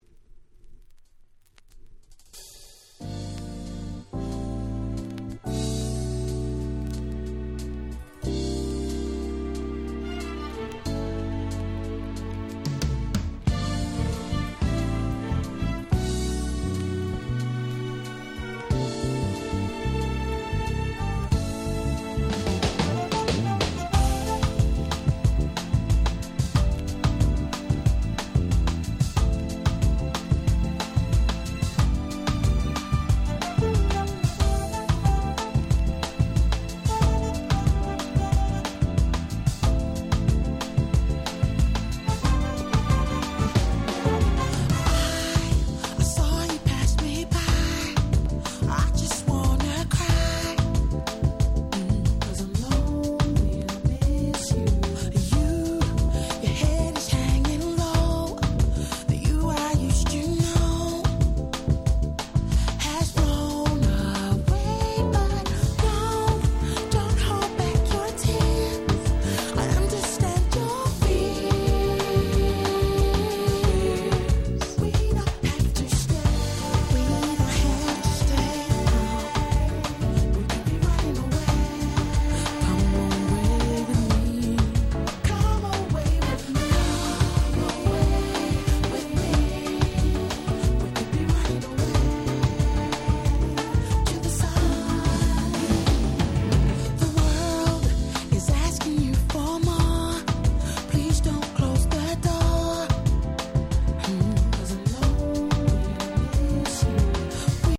UK R&B Classic LP !!